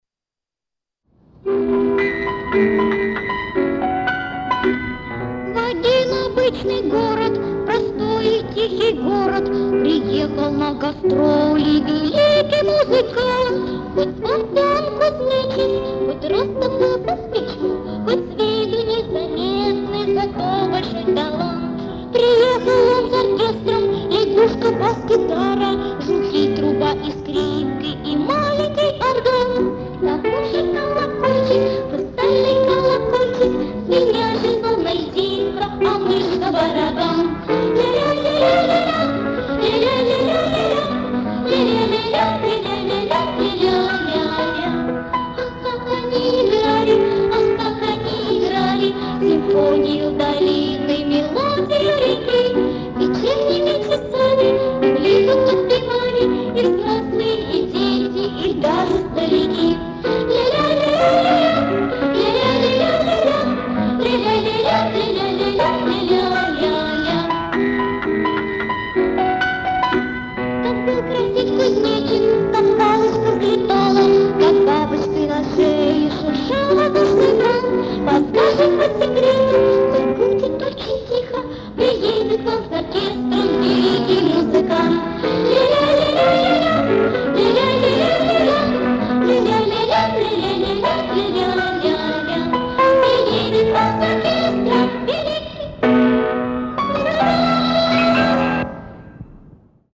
вокал, гитара
Детская песенка